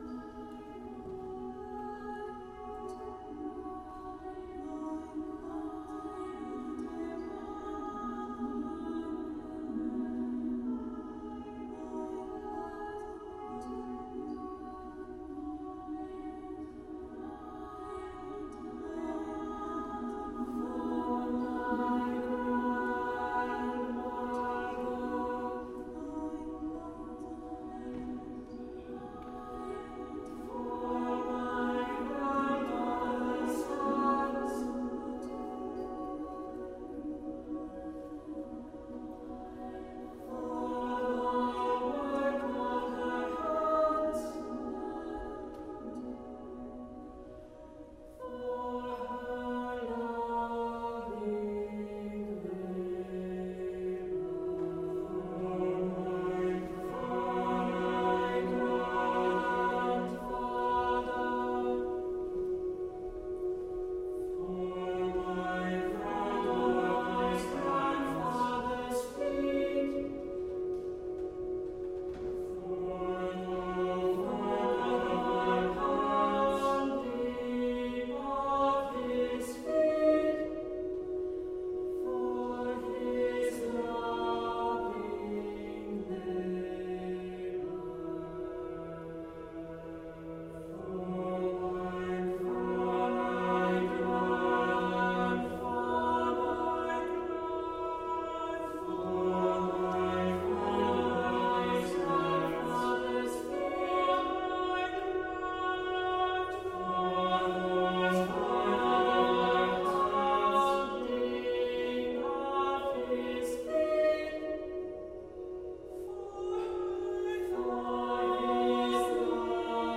Voicing: SATB divisi a cappella